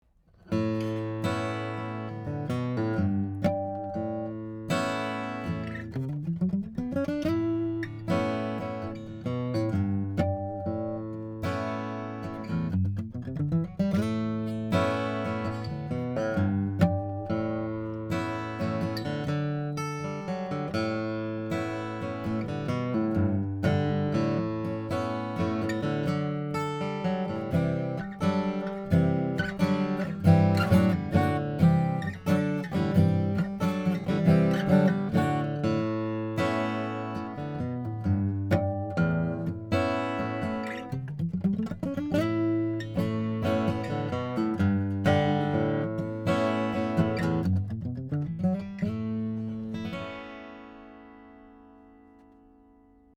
The basses are loud and full, and the midrange detail is sweet, so the the guitar is very fun to play, and hard to put down.
The guitar was recorded with a pair of Schoeps CMT541 condenser mics uning Ocean Audio preamps and Metric Halo ULN-8 interface. No reverb, EQ, compression or any effects -- just the straight tone from the guitar:
(Original, in A)